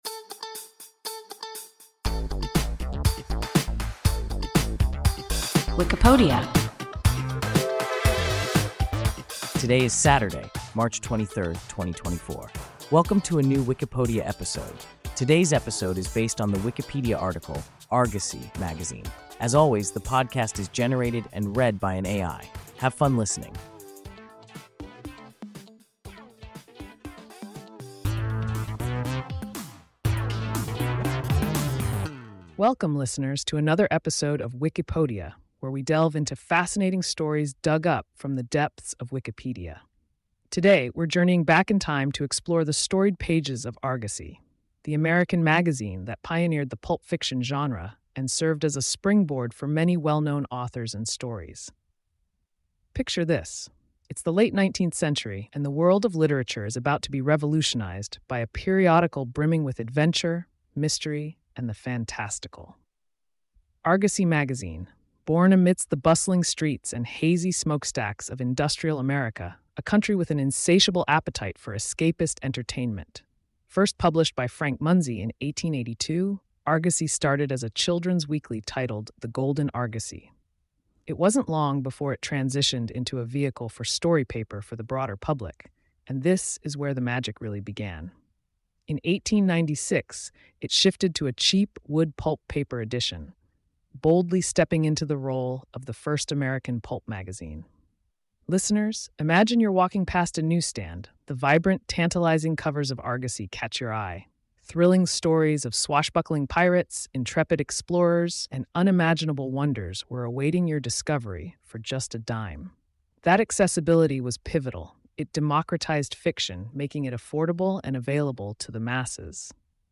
Argosy (magazine) – WIKIPODIA – ein KI Podcast